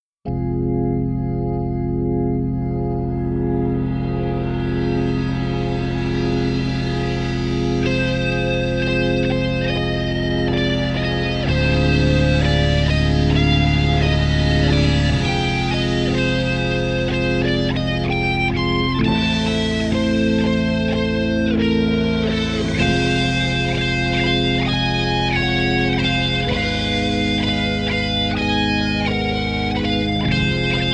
chamber music, rock, theatre and improvisation
Violin, cello, bassoon, saxes and accordion